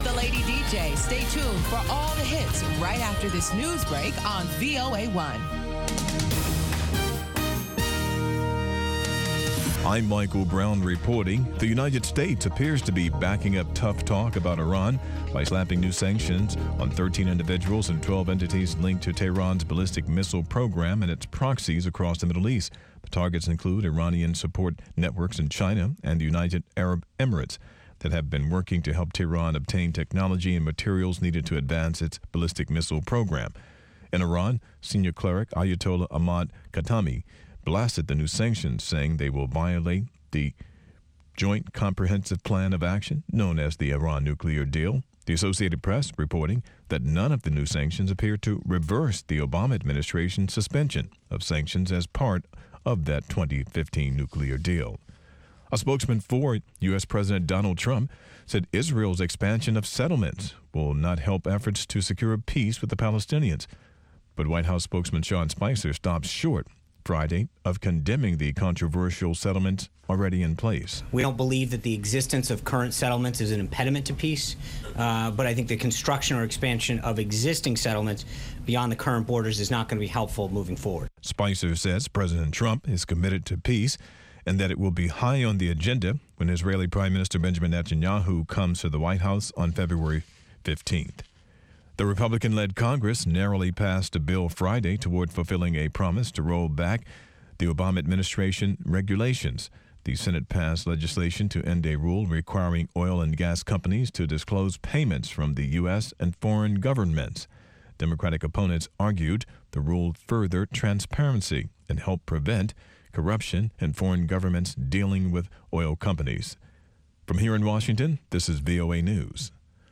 Una discusión de 30 minutos sobre los temas noticiosos de la semana con diplomáticos, funcionarios de gobiernos y expertos.